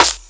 Snares
snarecrack.wav